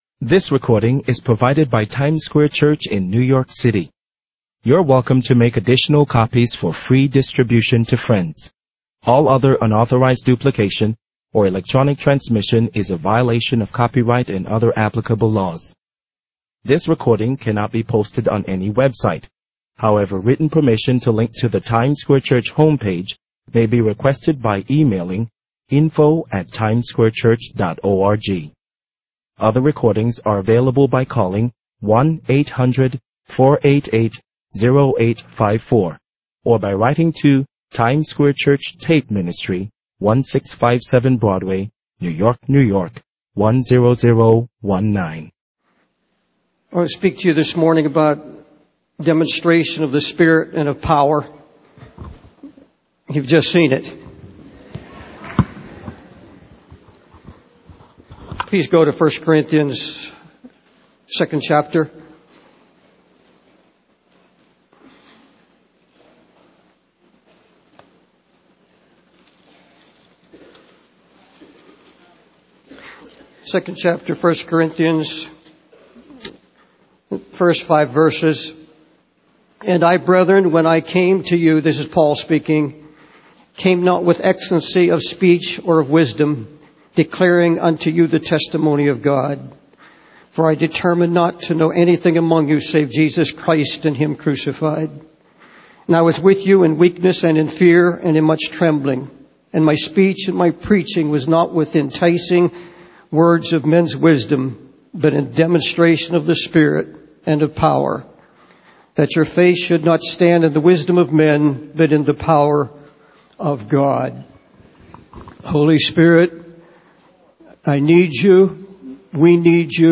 In this sermon, Paul emphasizes the importance of following his ways and teachings.